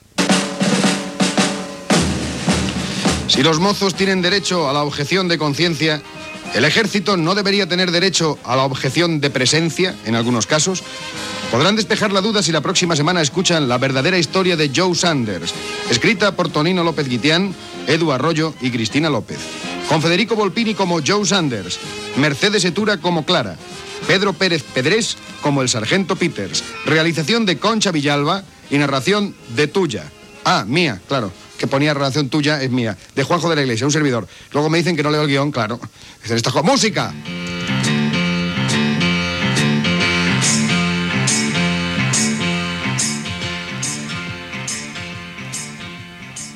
Crèdits finals del serial que oferia el programa.
Ficció